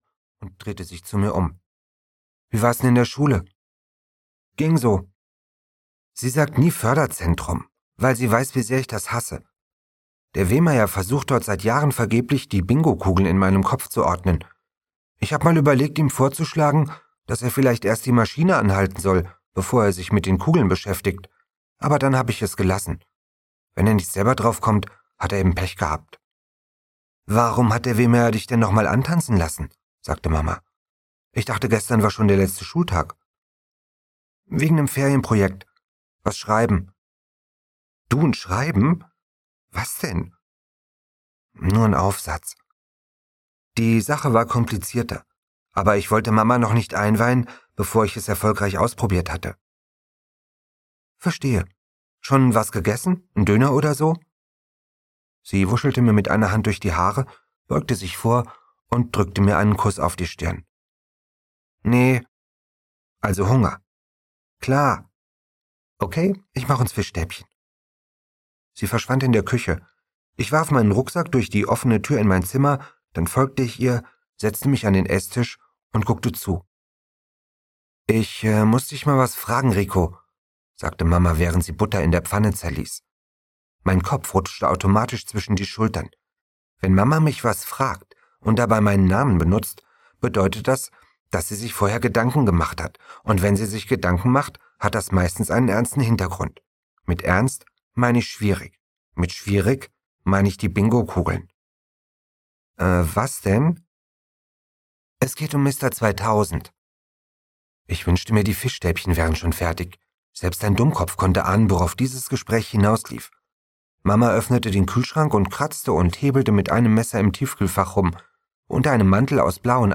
Rico und Oskar 1: Rico, Oskar und die Tieferschatten - Andreas Steinhöfel - Hörbuch